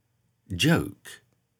2. In words with a stressed O followed by a consonant sound followed by a silent E that O often has an /əʊ/ sound. Play these flashcards to hear a native speaker, and click on the red arrows to flip them.
/dʒəʊk/